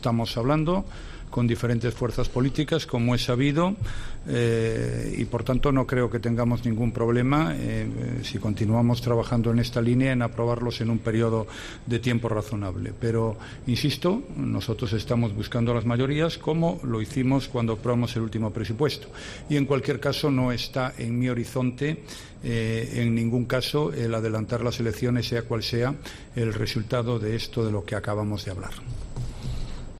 Rajoy se ha referido al retraso en la aprobación de las cuentas del próximo año en una conferencia de prensa en la Casa Blanca, junto el presidente de los EEUU, Donald Trump, después del almuerzo de trabajo que ambos han mantenido.